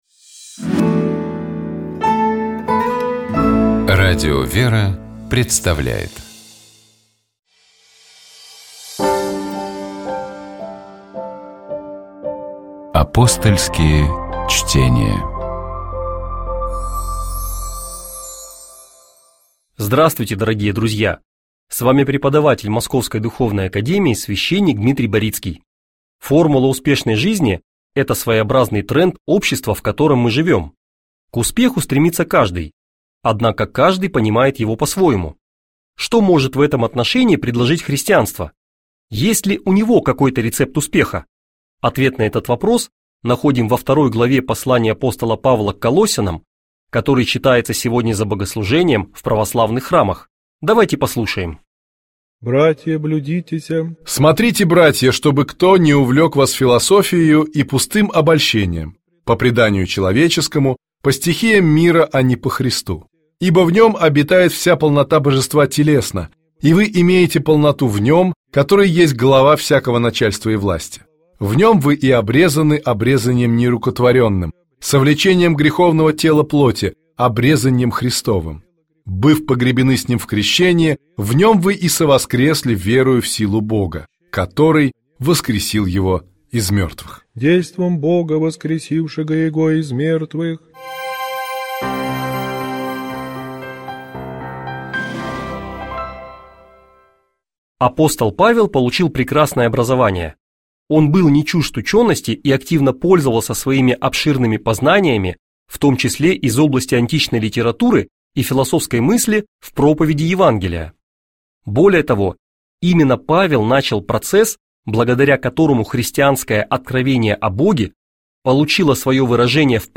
Кульминацией ночной пасхальной службы является Пасхальный канон, церковное песнопение, состоящее из нескольких песней и исполняемое на каждом вечернем богослужении до Праздника Вознесения.